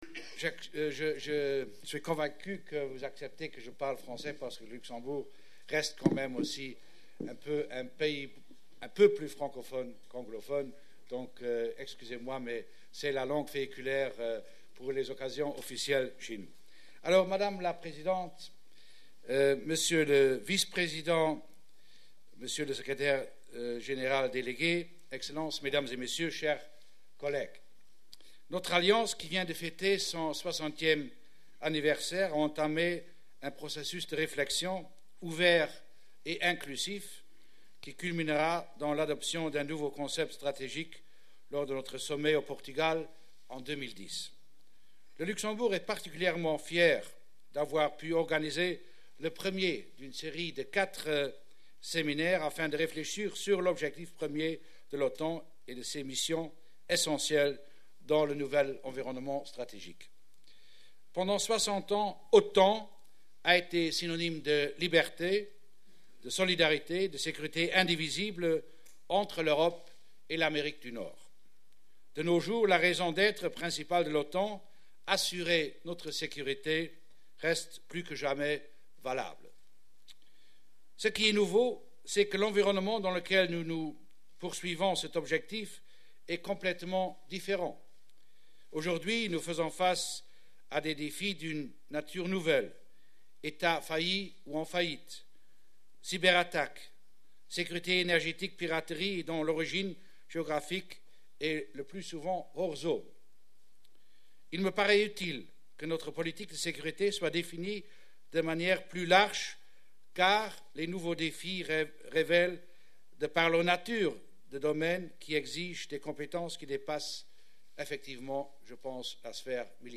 NATO’s new Strategic Concept discussed at Luxembourg seminar
09:30 Welcome remarks
H.E. Jean Asselborn, Minister of Foreign Affairs, Luxembourg